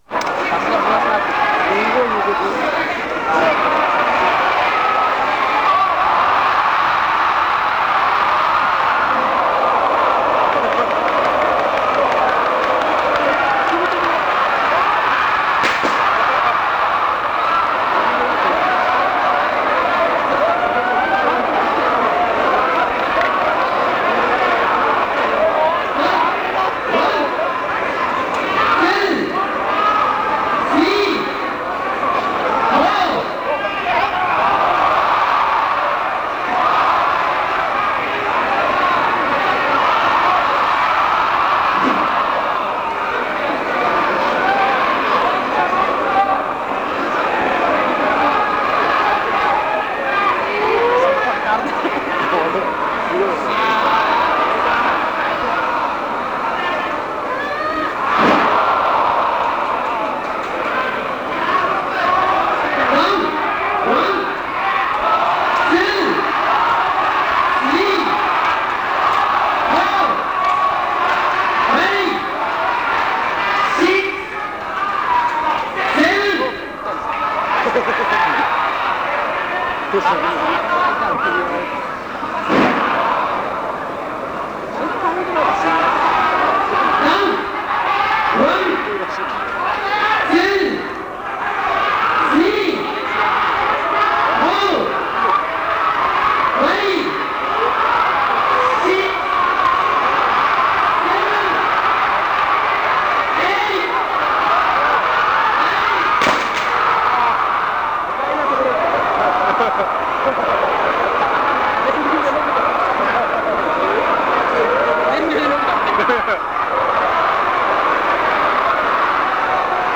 (1)FMW｢大仁田厚vsターザン後藤，ノーロープ有刺鉄線電流爆破マッチ」1990.8.4 汐留貨物駅跡地(31.9MB)